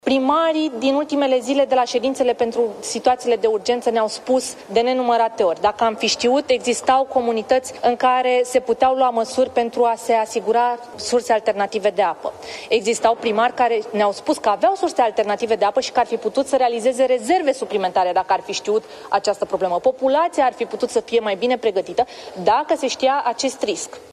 Criza apei potabile, care afectează peste 100.000 de locuitori, putea fi prevenită, mai spune Diana Buzoianu